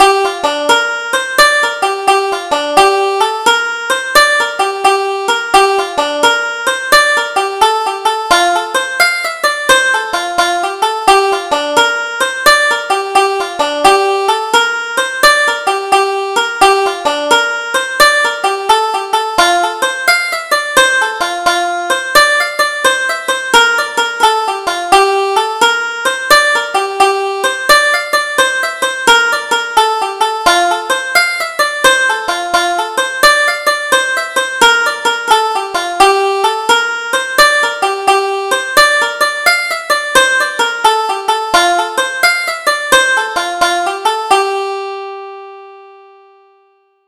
Double Jig: The Twopenny Jig